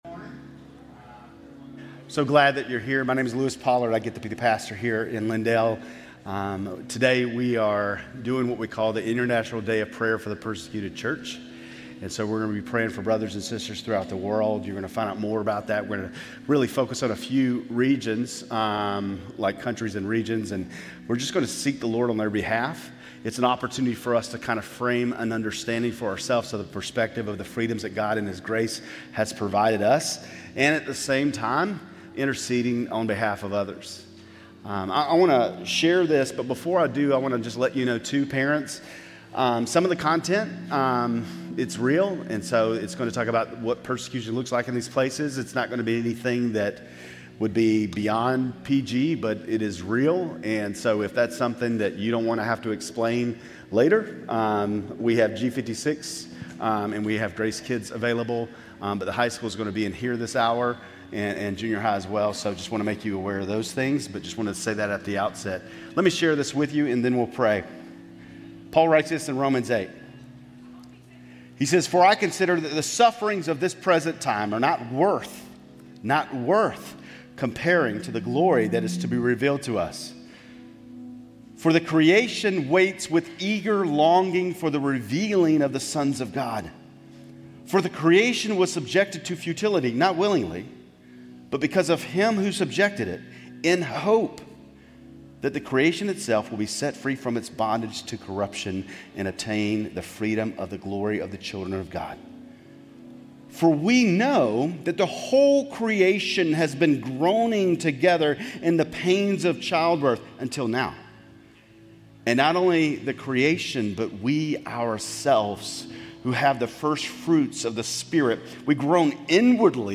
Grace Community Church Lindale Campus Sermons 11_9 Lindale Campus Nov 10 2025 | 01:09:09 1x 00:00 / 01:09:09 Subscribe Share RSS Feed Share Link Embed